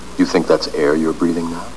air
Category: Sound FX   Right: Personal